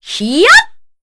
Veronica-Vox_Casting4_kr.wav